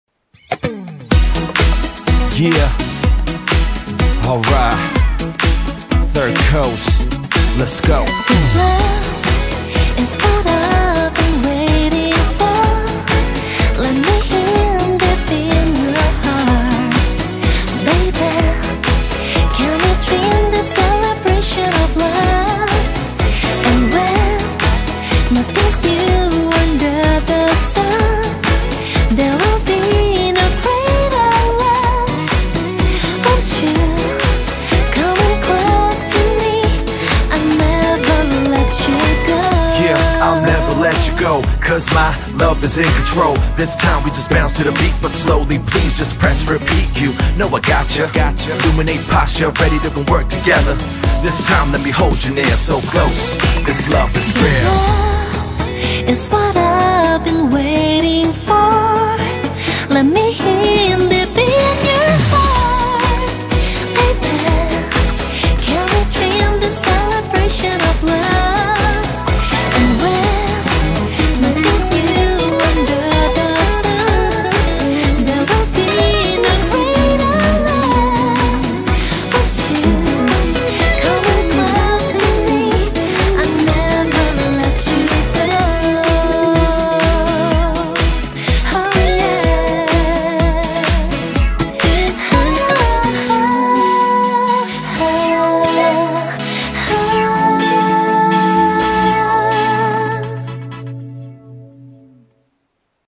BPM125
Audio QualityCut From Video